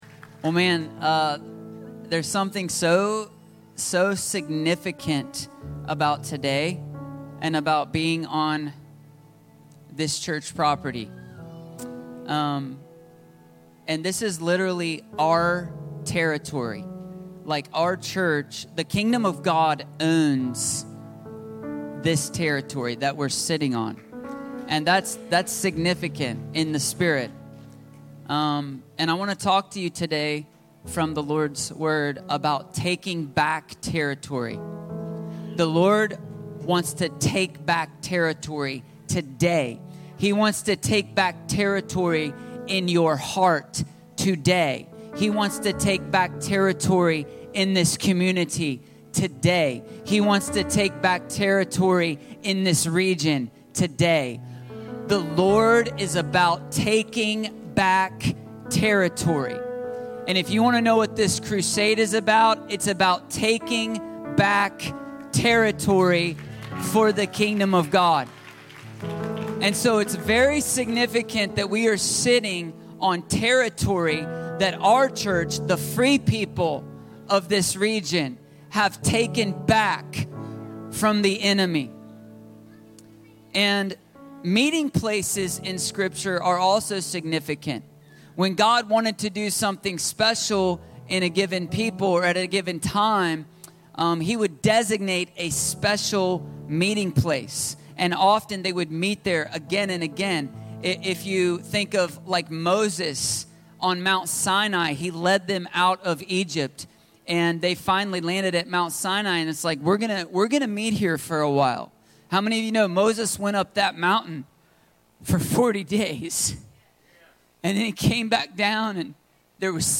Taking Back Territory - Stand Alone Messages ~ Free People Church: AUDIO Sermons Podcast